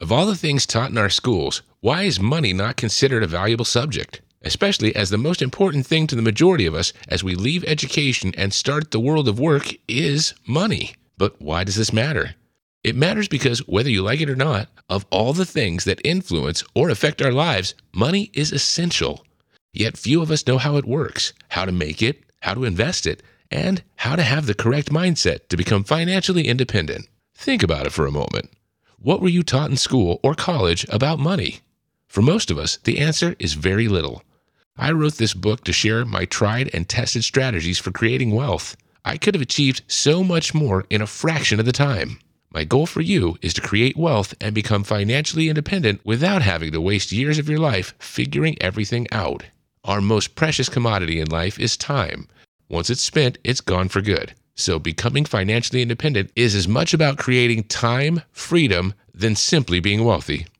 English (American)
Natural, Distinctive, Accessible, Versatile, Friendly
Audio guide